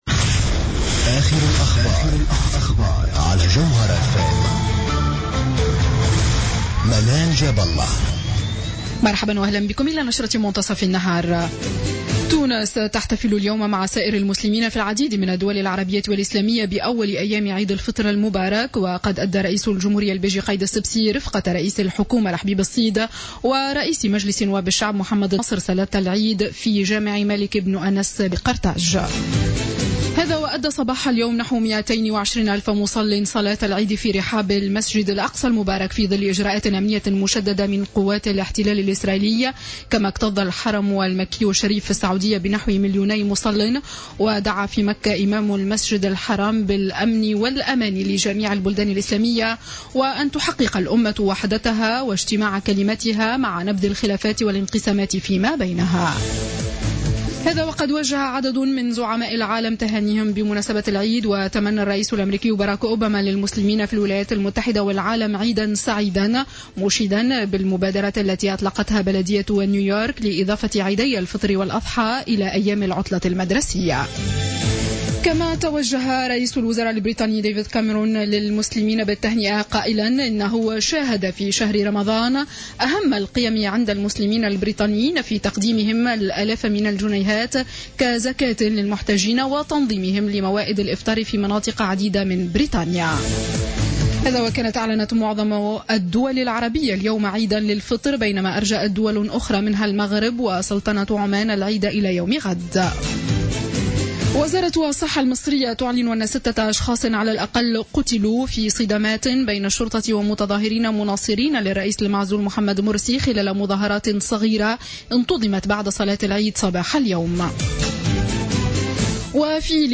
نشرة أخبار منتصف النهار ليوم الجمعة 17 جويلية 2015